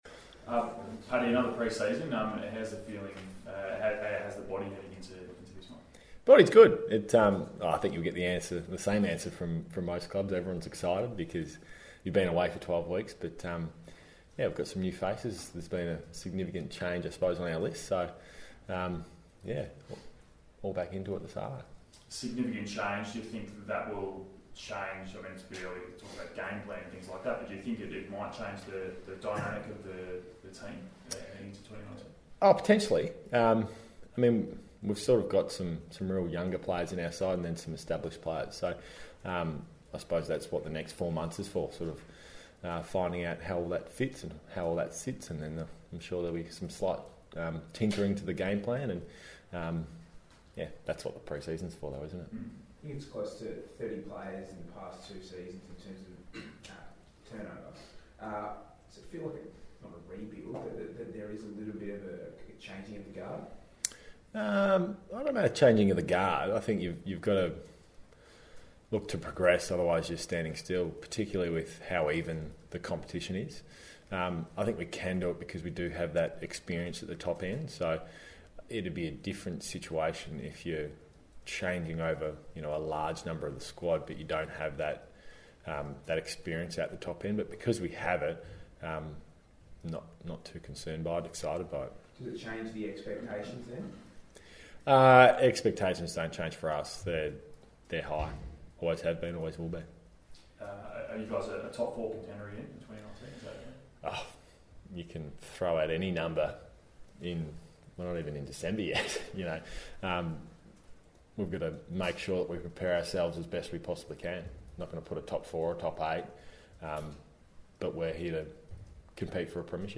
Geelong star Patrick Dangerfield faced the media ahead of his return to pre-season training.